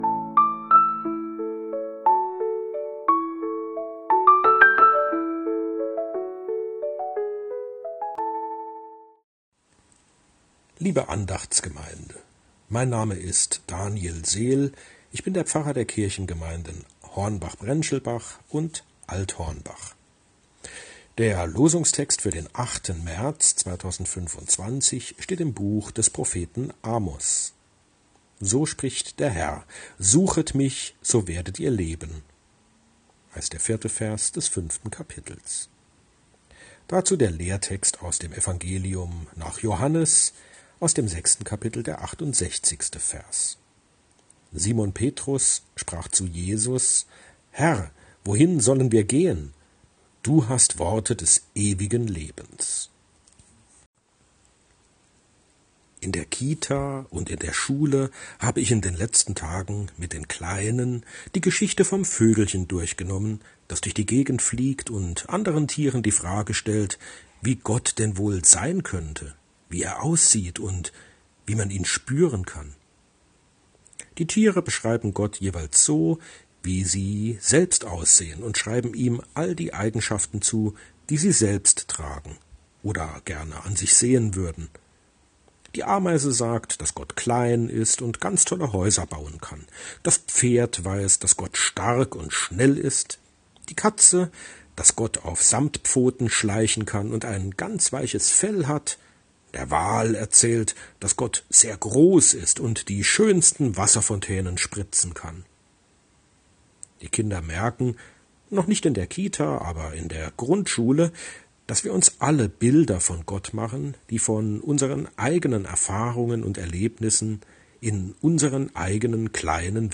Losungsandacht für Samstag, 08.03.2025